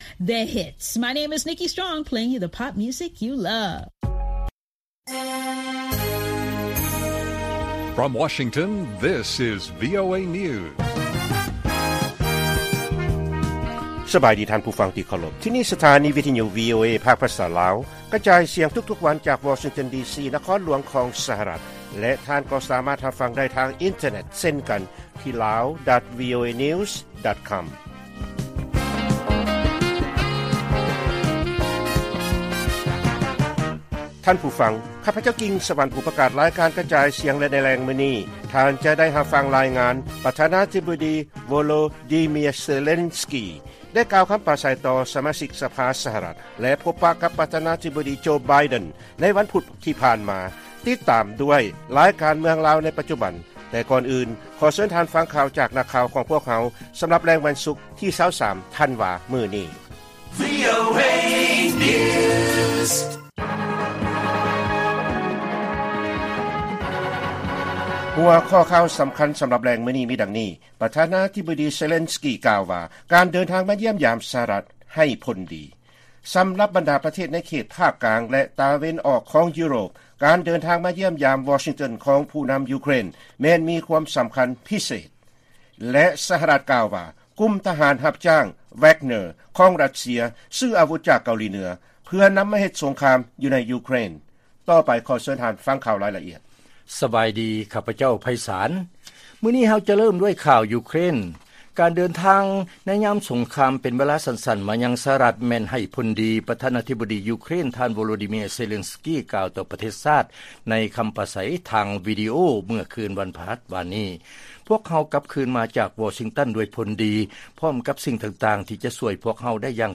ລາຍການກະຈາຍສຽງຂອງວີໂອເອ ລາວ: ປະທານາທິບໍດີເຊເລນສກີ ກ່າວວ່າ ການເດີນທາງມາສະຫະລັດ ແມ່ນ 'ໃຫ້ຜົນດີ'